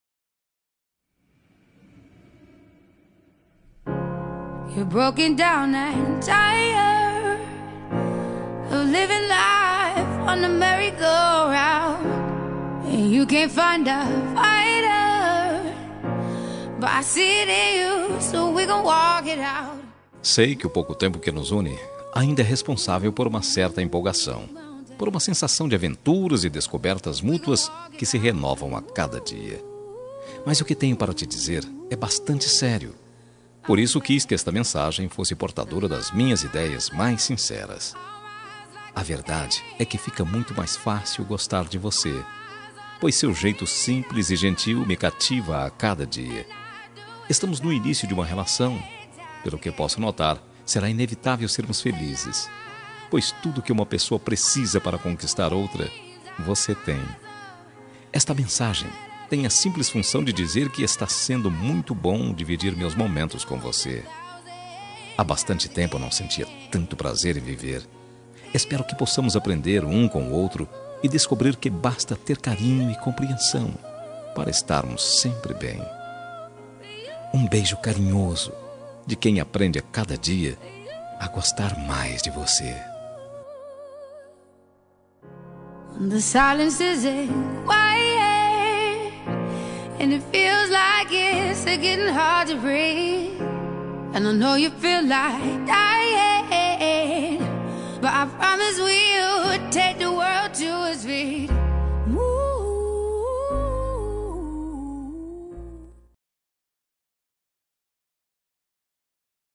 Telemensagem Para Ficante – Voz Masculina – Cód: 5430